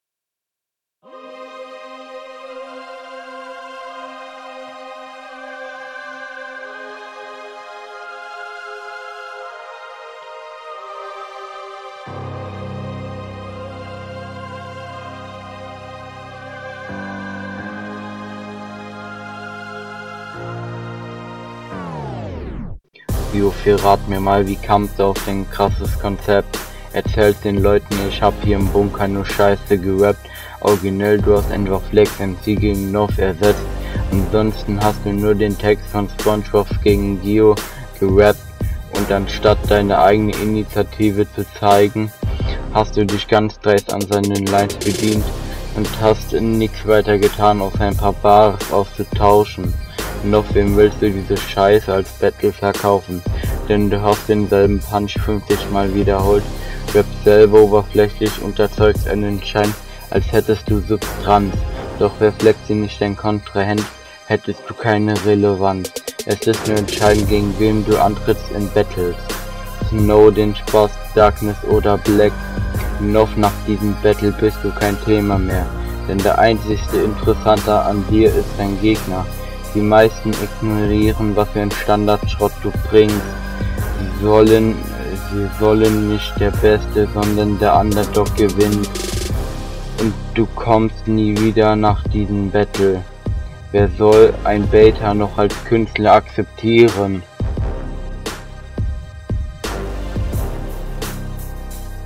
Klingt noch sehr unroutiniert, kann auch nicht wirklich gerappt, sondern eher gesprochen.
wirkt sehr holprig und man versteht die hälfte nicht...